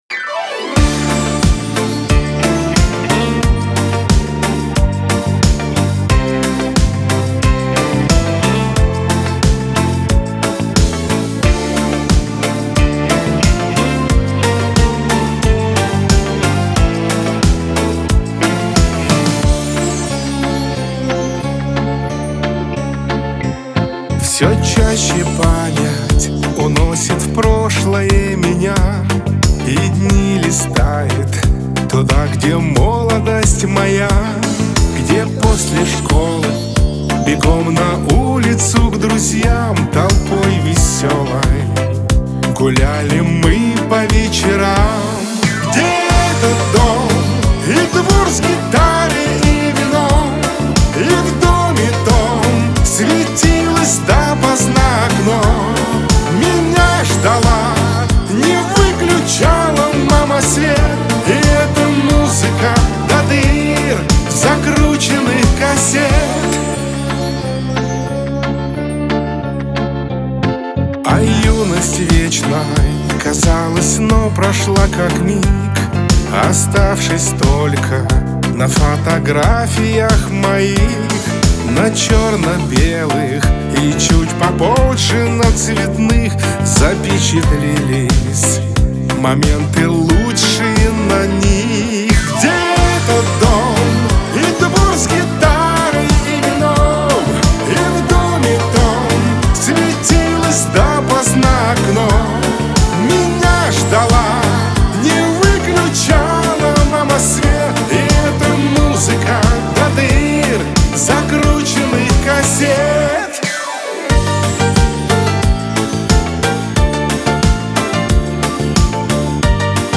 pop , танцы , диско
Веселая музыка , эстрада